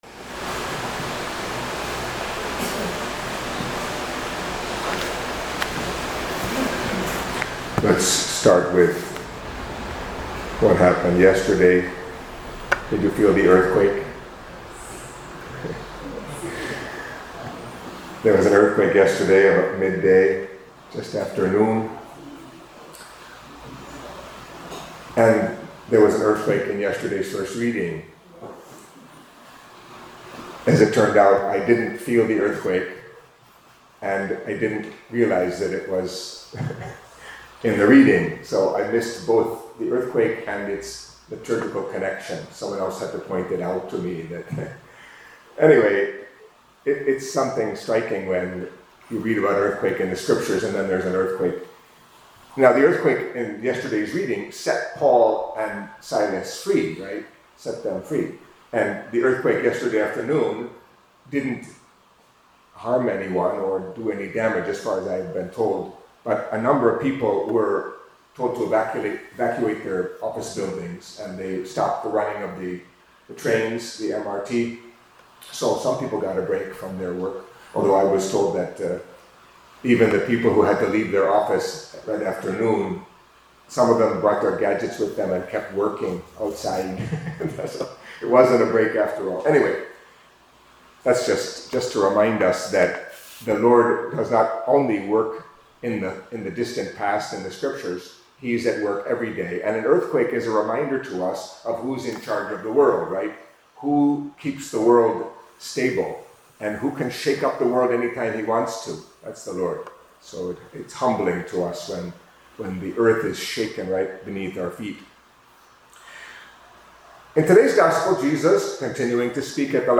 Catholic Mass homily for Wednesday of the Sixth Week of Easter